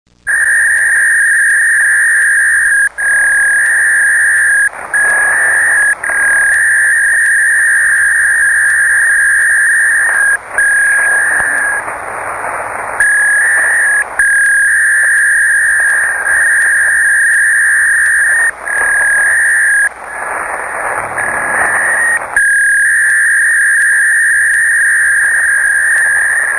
Pour représenter un niveau 1 en format audio, une tonalité de 2200 Hz est générée.
Exemple audio d'une transmission Packet
Voici deux exemples (fichiers MP3) de transmission Packet tel que l'on peut entendre sur les ondes :
Transmission Packet à 300 bauds (HF)